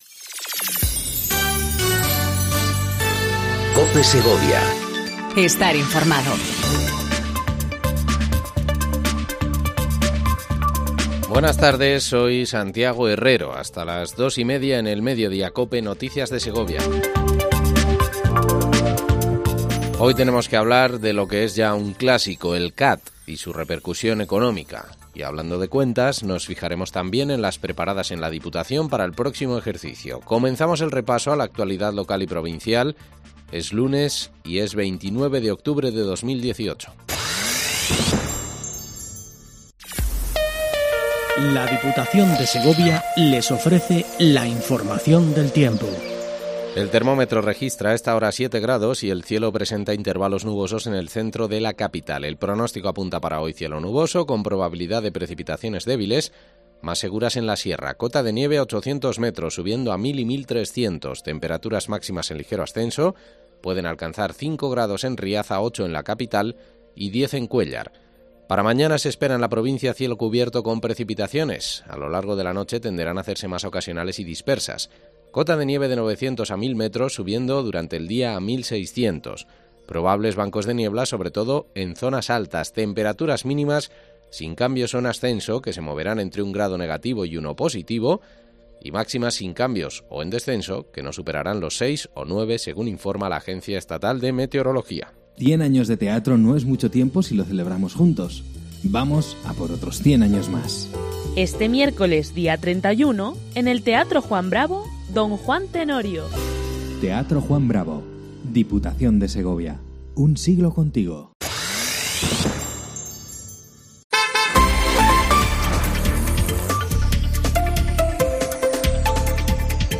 INFORMATIVO MEDIODÍA COPE SEGOVIA 14:20 DEL 29/10/18